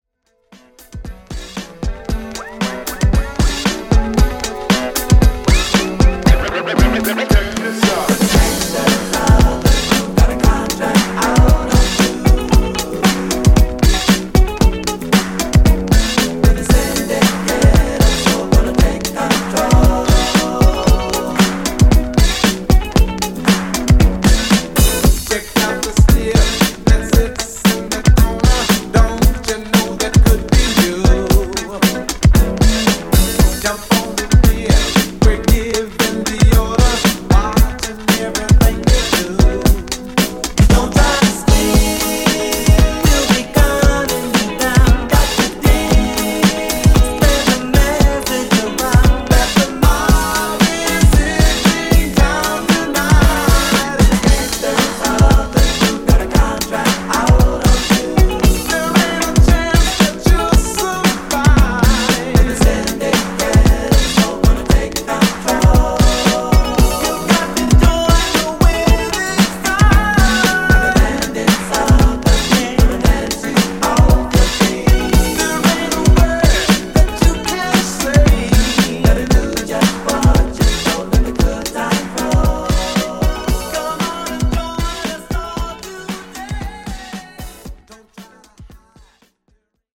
80s Redrum)Date Added